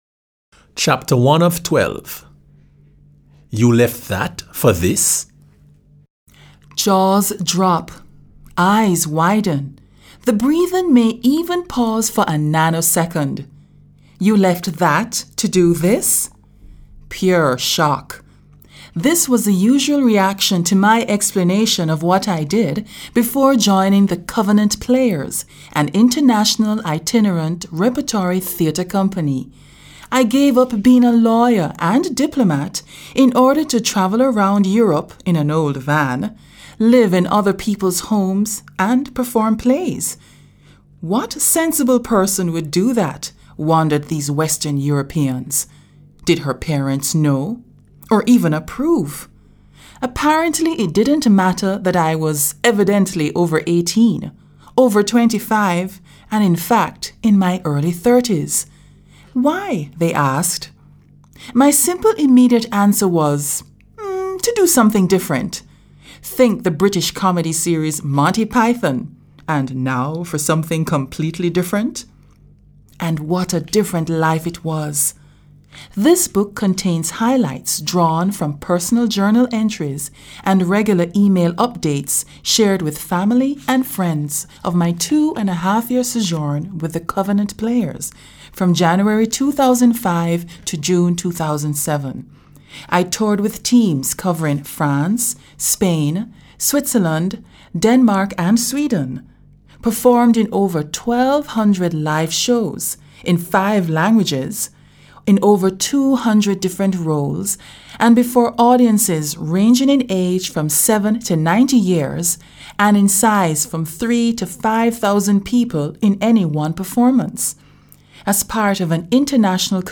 now also available in audio-book, paper back, on Amazon Kindle and Apple iBooks.